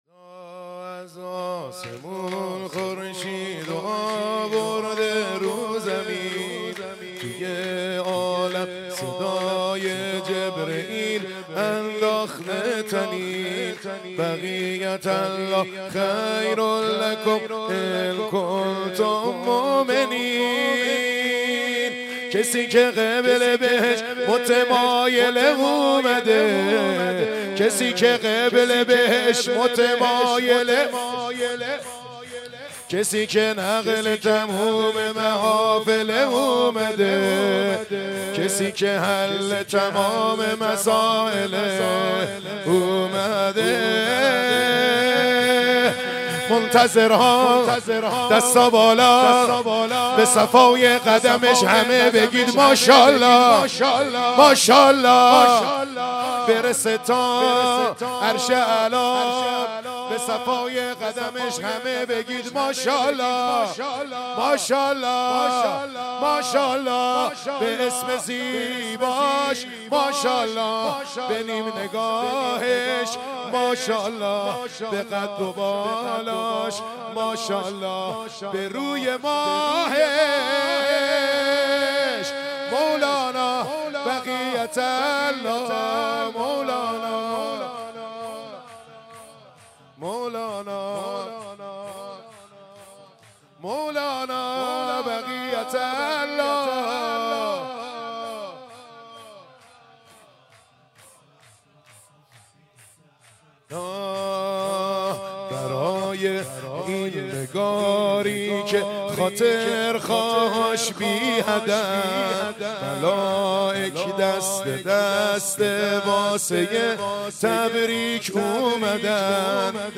حسینیه کربلا
شور1